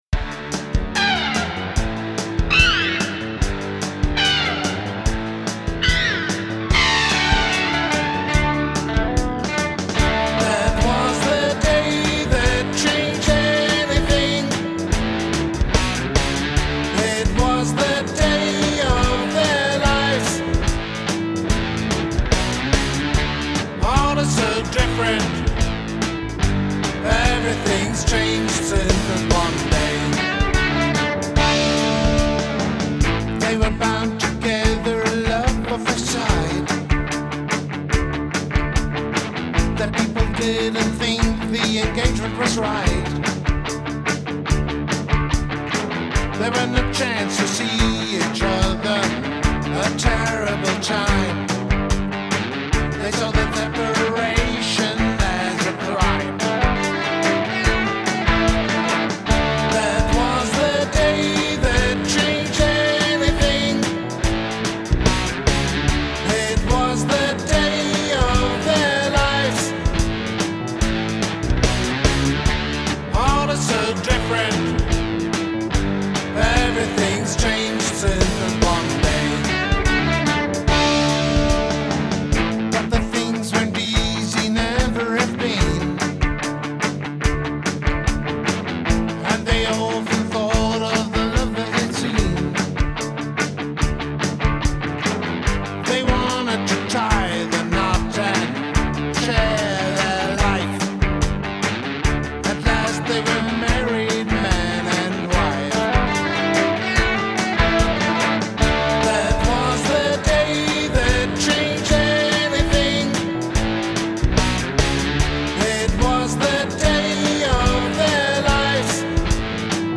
Guitar, Vocals, Keyboard